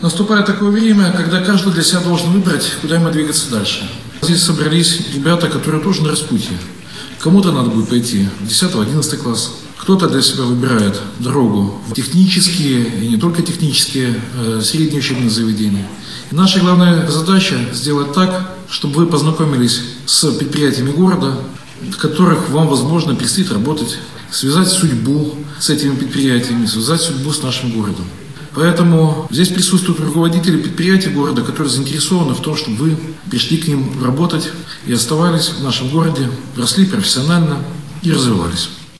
На базе городского Дома культуры состоялась ярмарка целевой подготовки «Барановичи-2023».
К собравшимся обратился председатель Барановичского горисполкома. Михаил Баценко пожелал ребятам определиться со сферой деятельности, в которой они хотели бы себя видеть в будущем.